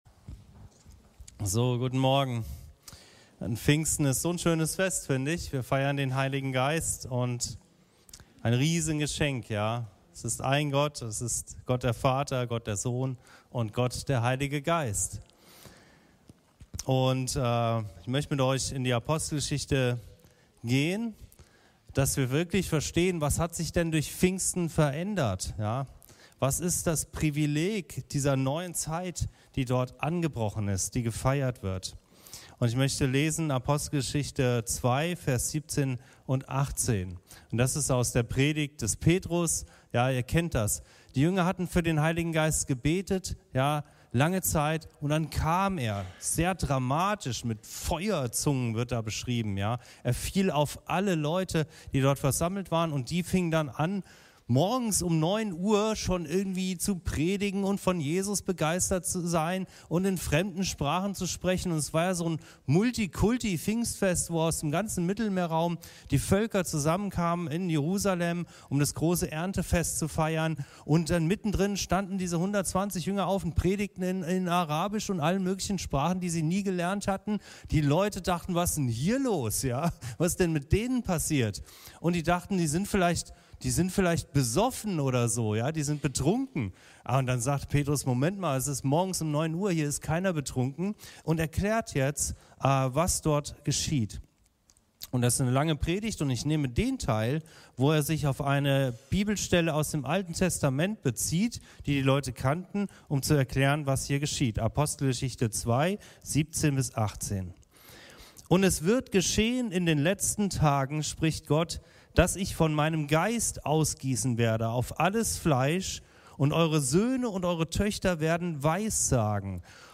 Prophetie, Visionen und Träume als Kennzeichen des Neuen Bundes ~ Anskar-Kirche Hamburg- Predigten Podcast